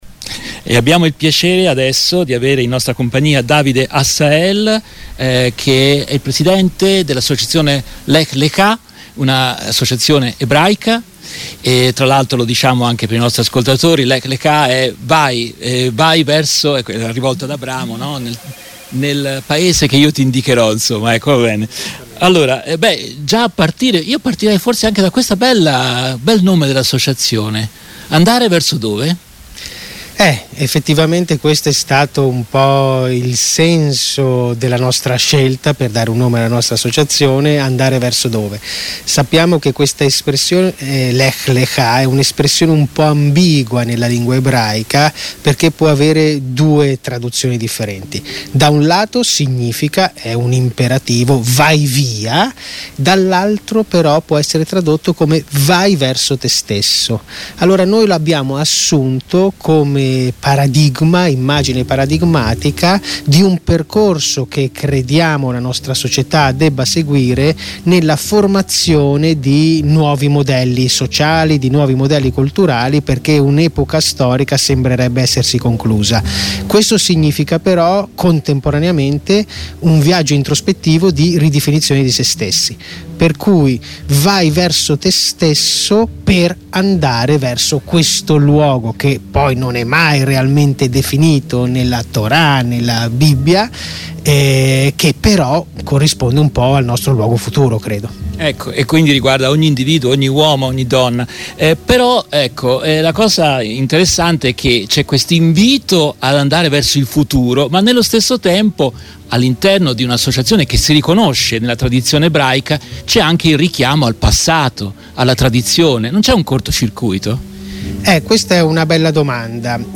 A margine dei lavori del Villa Aurora Meeting, promosso dalla Facoltà avventista di Firenze sul tema "I tuoi occhi videro il mio corpo.